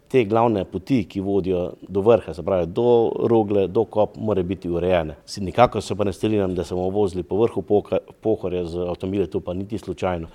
V Mislinji je včeraj potekala okrogla miza o tem, kako se izogniti pastem množičnega turizma na Pohorju.
In kako bomo turiste pripeljali do turističnih točk na Pohorju? Mislinjski župan Bojan Borovnik: